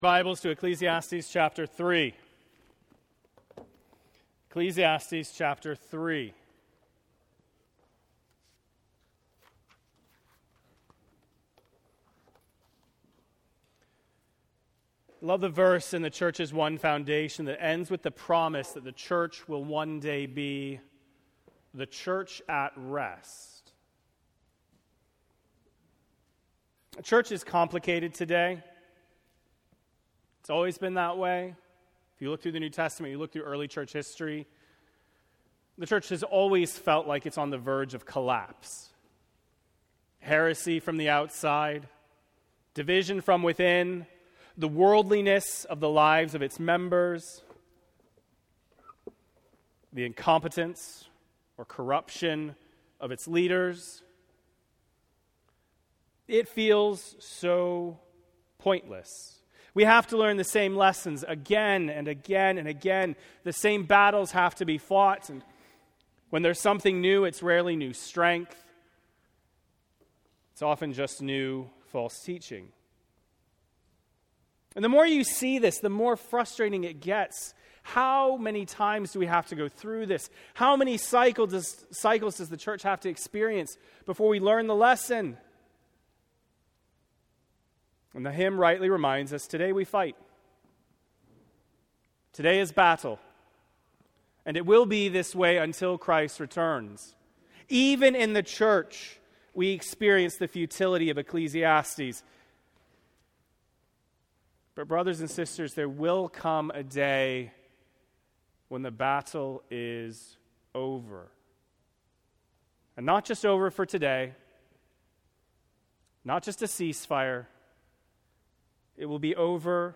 Bloomington Bible Church Sermons